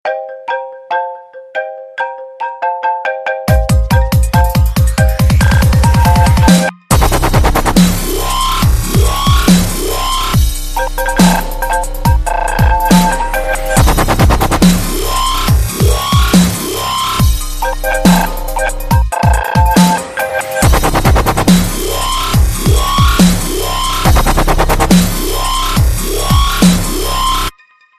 DubStep Remix